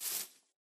grass2.ogg